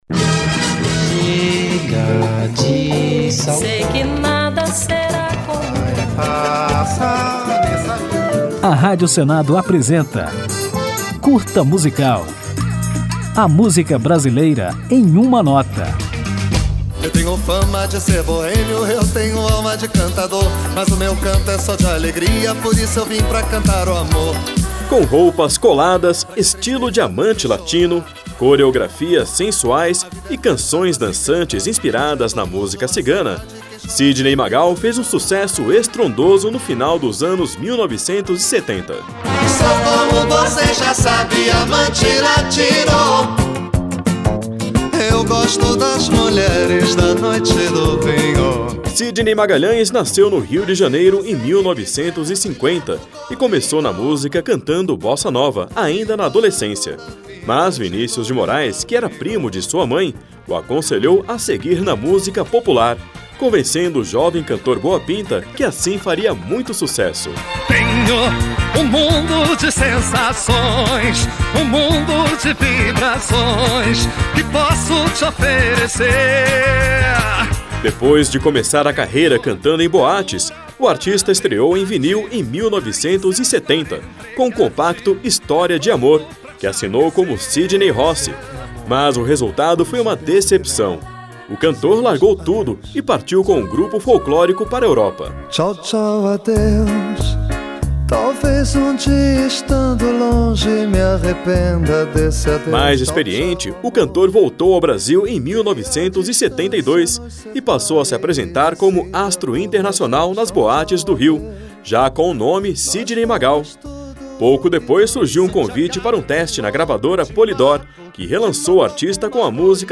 Ao final do programa, ouviremos Sidney Magal com o sucesso Sandra Rosa Madalena, "a Cigana".